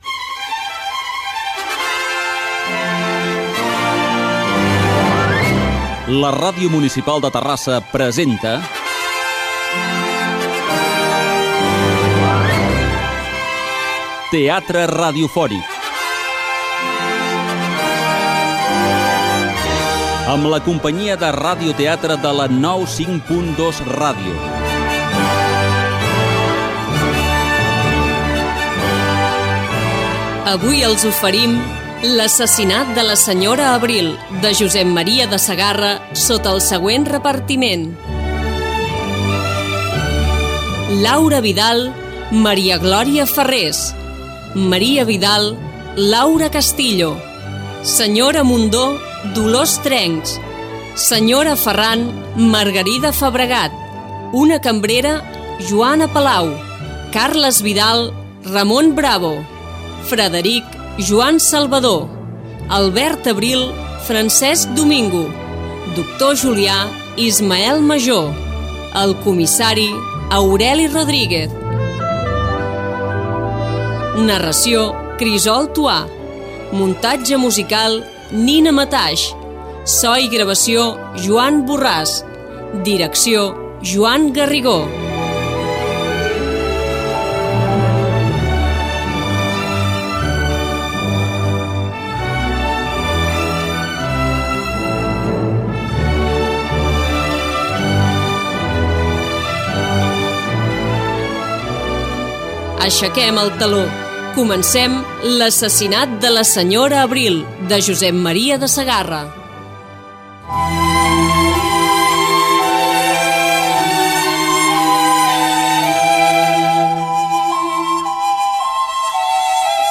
Teatre radiofònic
El narrador situa l'acció.
Ficció
Interpretat per la Companyia de la 95.2 Ràdio.